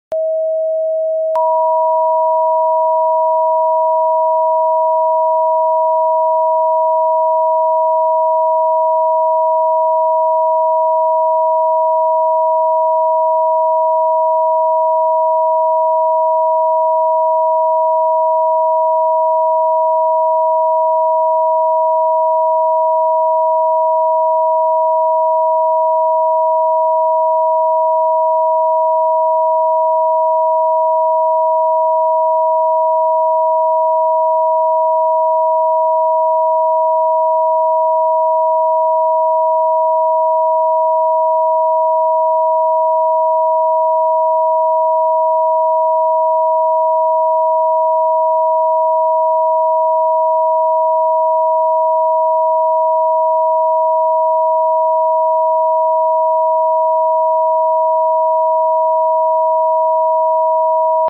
639 + 963 Hz